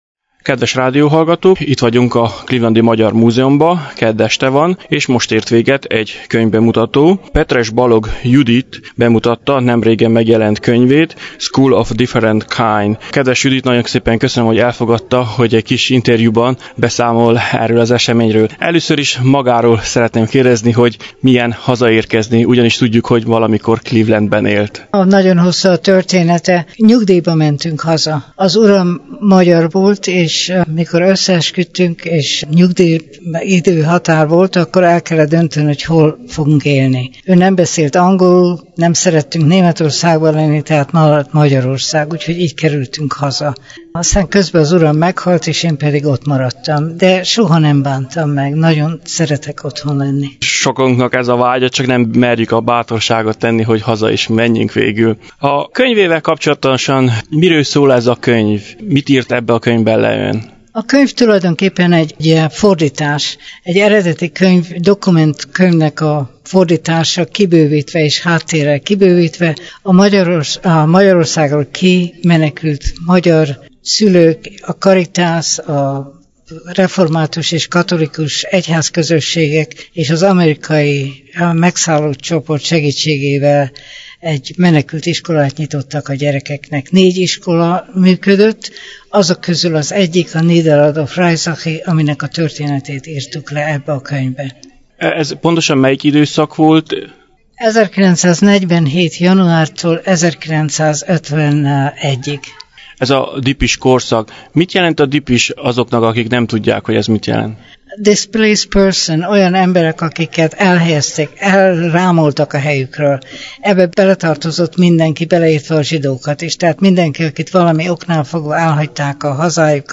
A könyvbemutató után lehetőségünk adódott, hogy egy interjút készítsünk az írónővel: